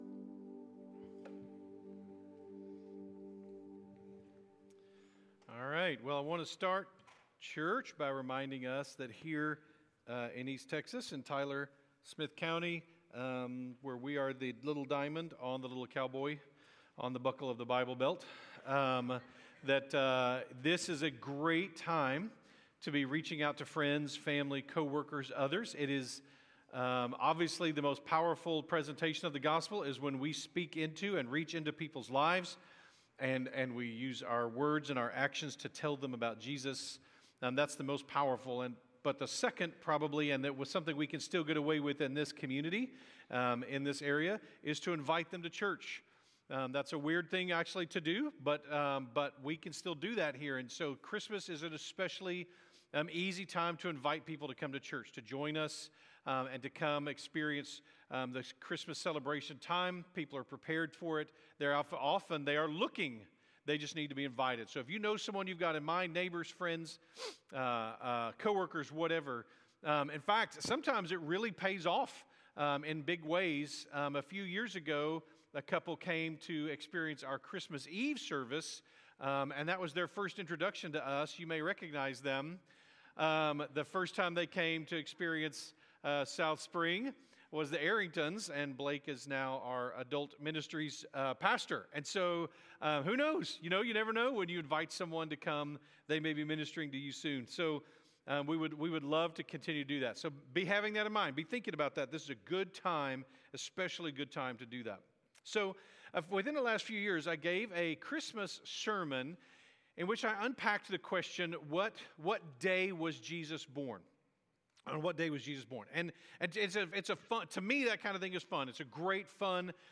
by South Spring Media | Nov 23, 2025 | 2025 Sermons, Advent 2025, Sermons | 0 comments